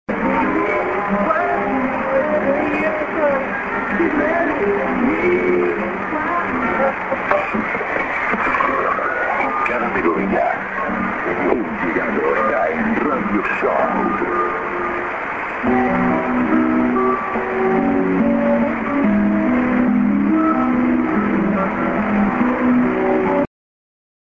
music-ID:Radio Show(man)->music